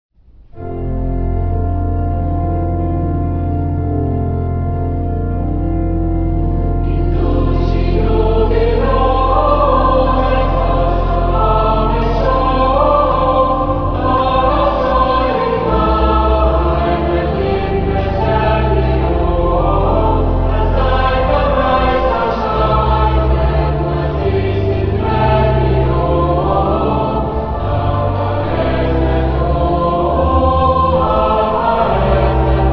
organ.